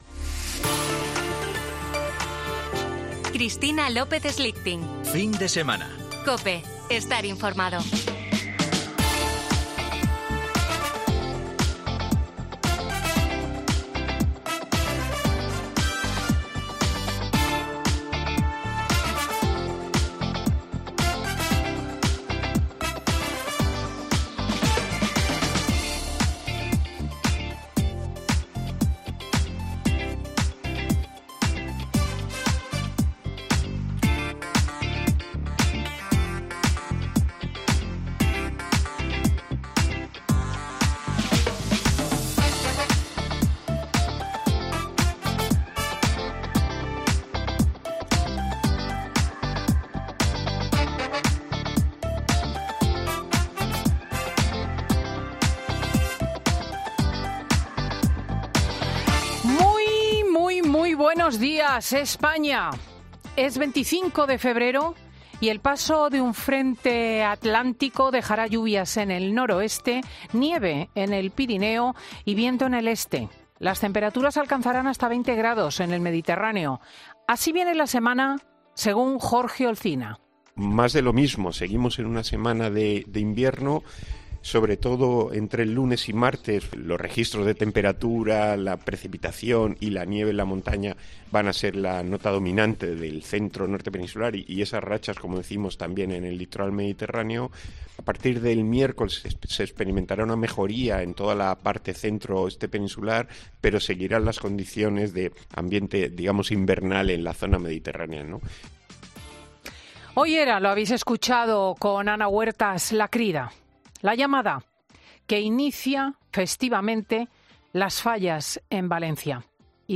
Escucha el editorial de Cristina L. Schlichting del domingo 25 de febrero